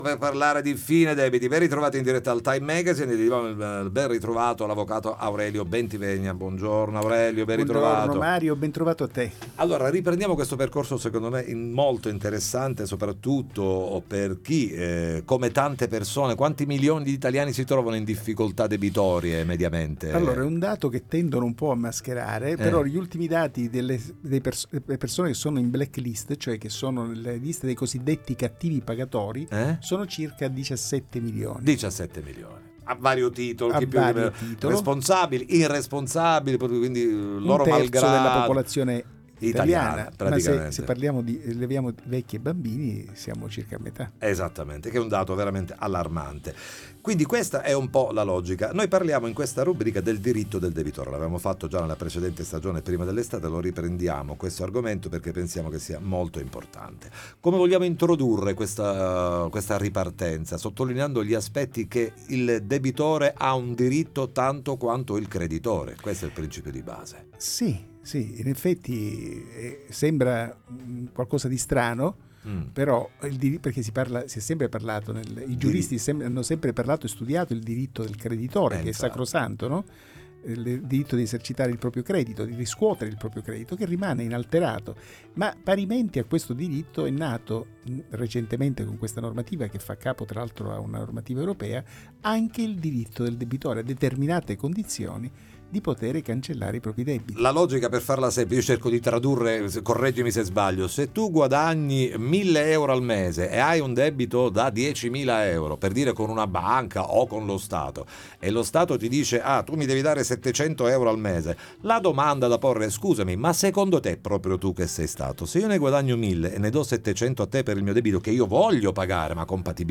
5a Puntata Interviste Time Magazine 27/10/2025 12:00:00 AM